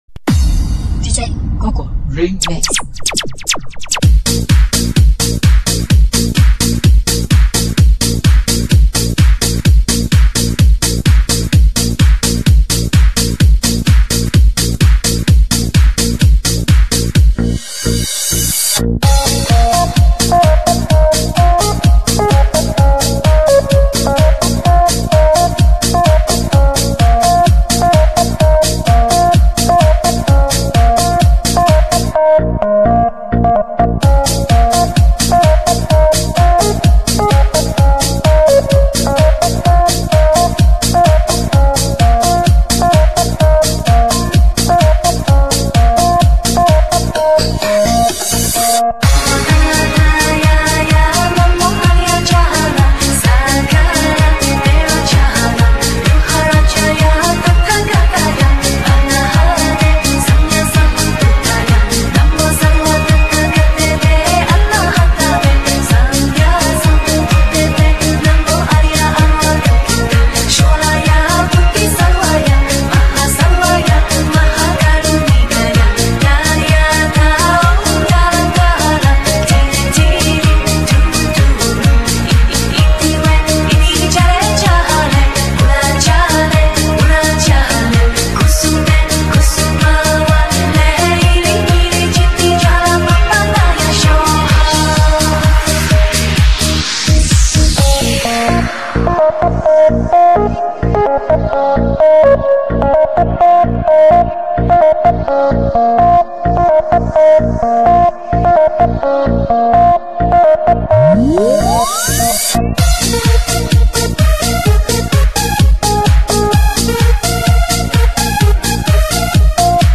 唱片类型：DJ
解禁香巴拉的千年传奇，年度西部DJ混音巨作，